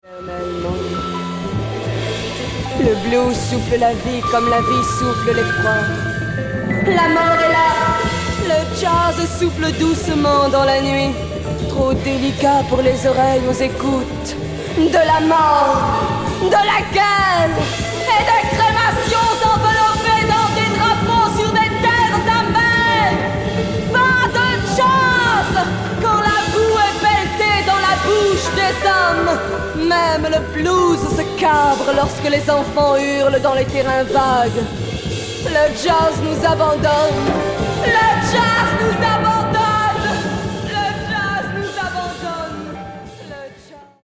フリー/レアグルーヴ/フレンチ・ジャズ